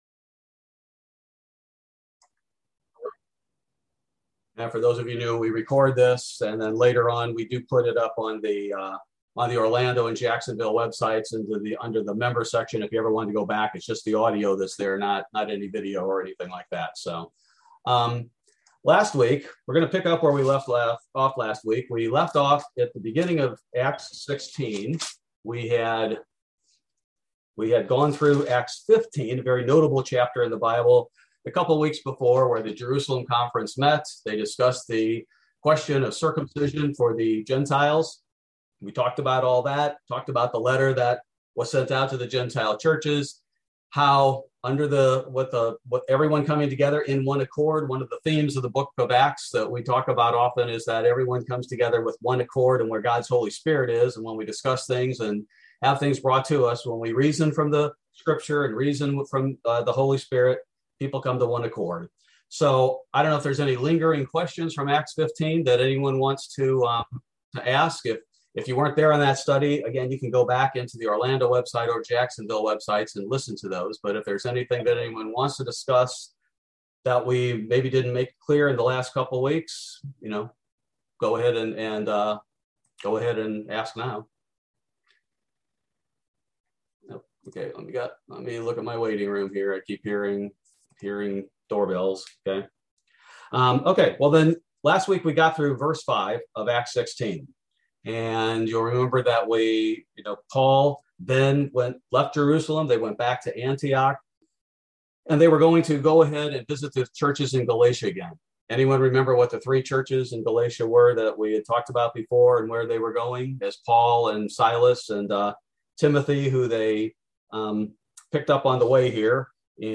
Bible Study: October 20, 2021